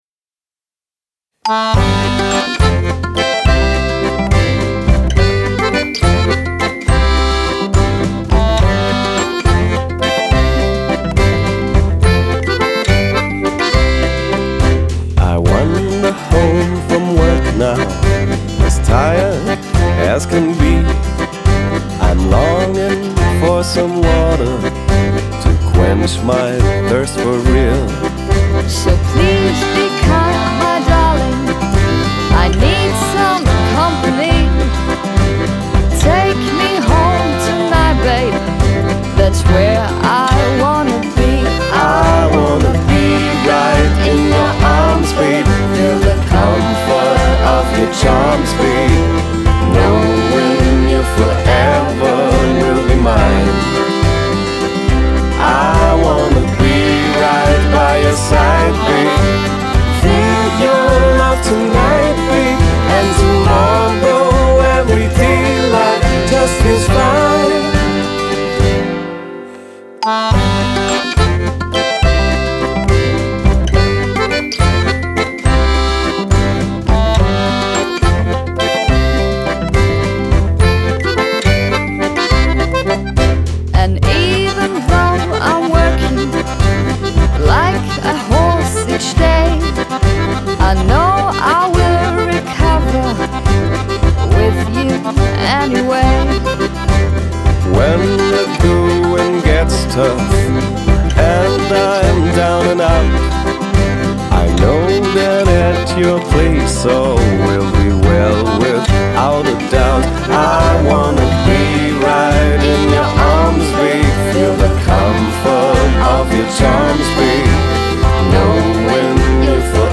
Wenn das fröhliche Lied erklingt, versammeln sich die Kinder voller Vorfreude unter der riesigen Kürbisdusche – ein spritziges Highlight, das für leuchtende Augen sorgt!